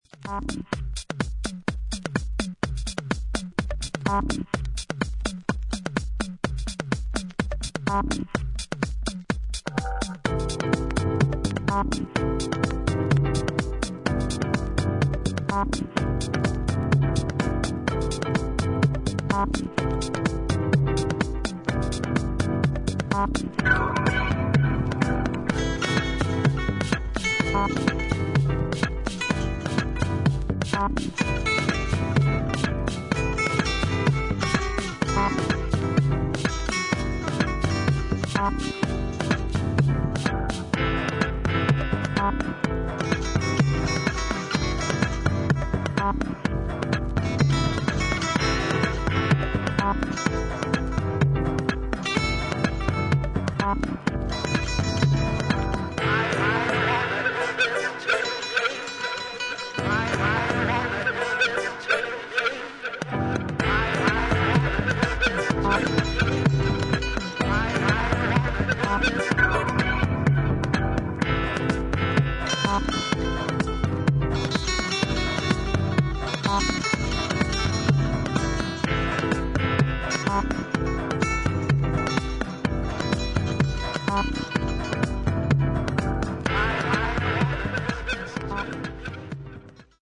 先見性のあるオリジナリティと、普遍的なダンスミュージックの魅力を内包させた秀逸な一枚です。